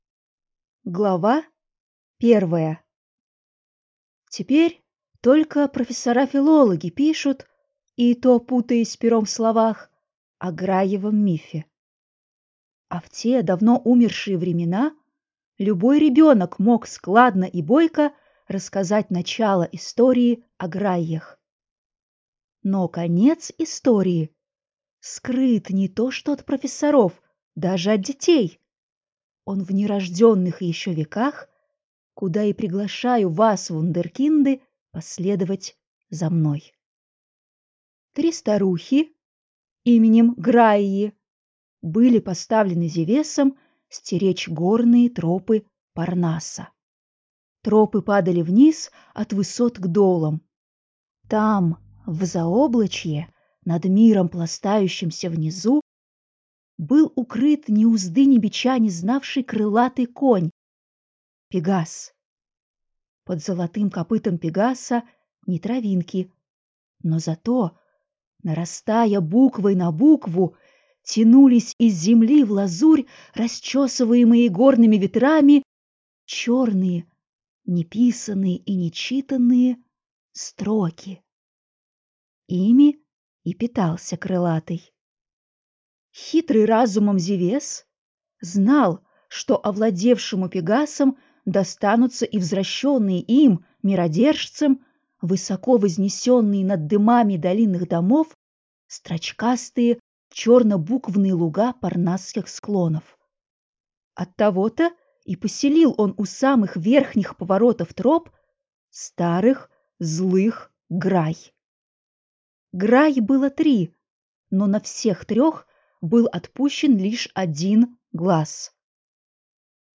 Аудиокнига Грайи | Библиотека аудиокниг